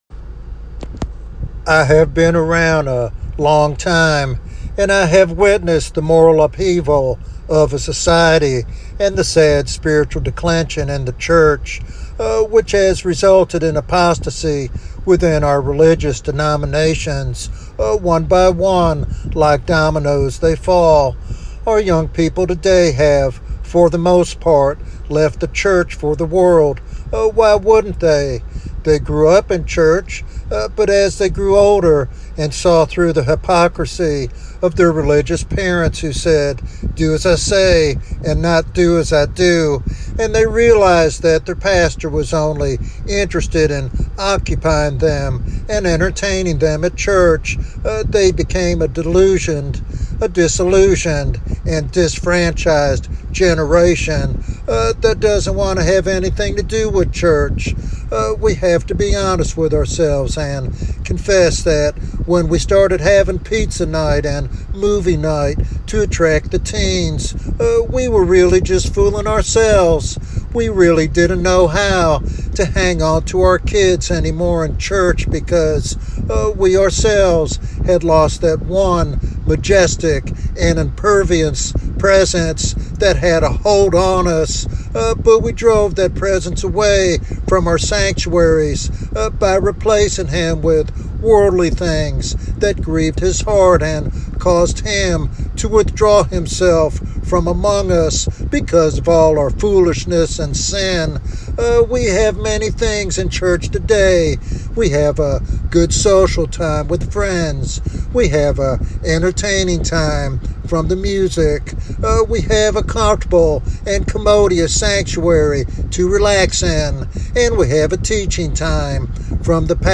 This sermon challenges believers to confront hypocrisy, apathy, and self-indulgence, urging a heartfelt restoration of God's majesty in their lives.